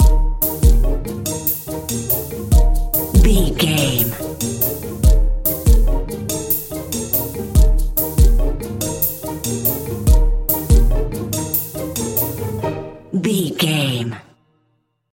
Ionian/Major
E♭
percussion
synthesiser
piano
strings
silly
circus
goofy
comical
cheerful
perky
Light hearted
quirky